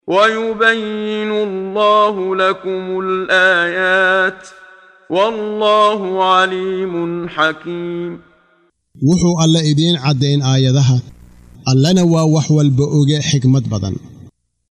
Waa Akhrin Codeed Af Soomaali ah ee Macaanida Suuradda An-Nuur ( Nuurka ) oo u kala Qaybsan Aayado ahaan ayna la Socoto Akhrinta Qaariga Sheekh Muxammad Siddiiq Al-Manshaawi.